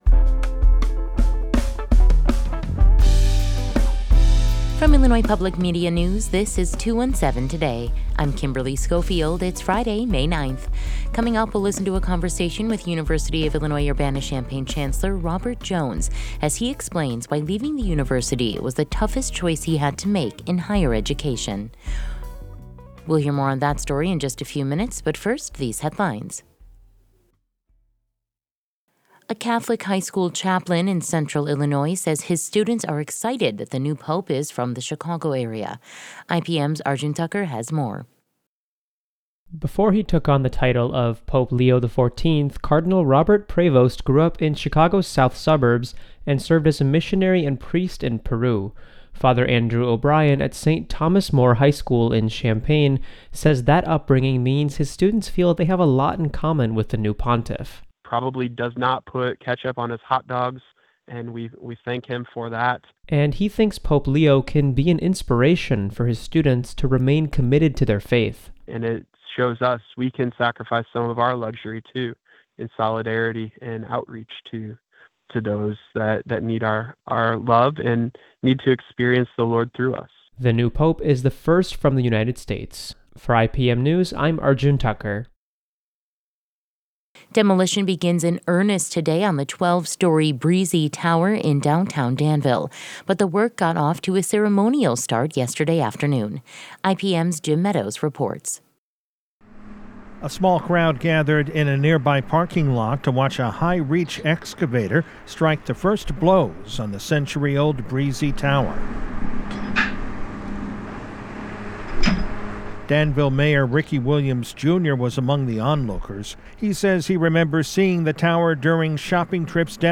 In today's deep dive, we’ll listen to a conversation with University of Illinois Urbana-Champaign Chancellor Robert Jones, who explains why leaving the university was the toughest choice he had to make in higher education.